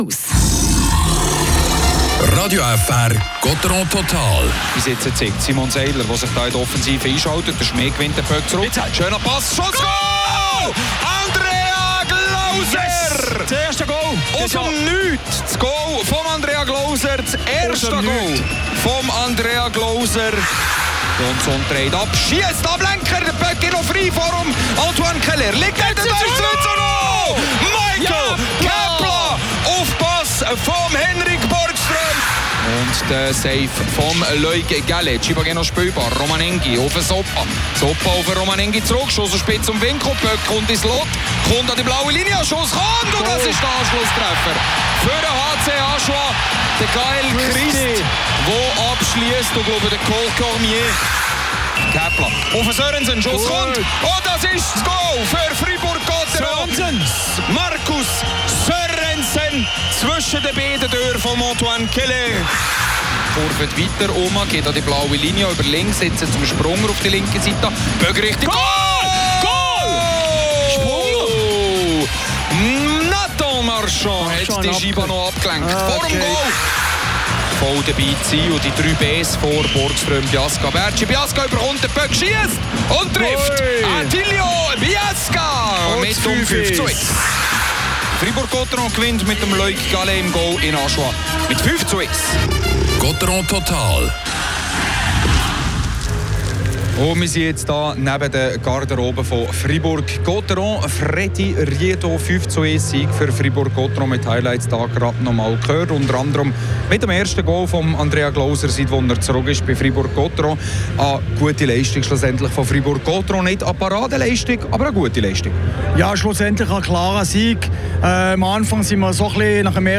Nach einem zähen Start findet Freiburg im Mitteldrittel den Tritt und holt sich letztlich einen souveränen 5:1-Pflichtsieg. Die Stimmen zum Spiel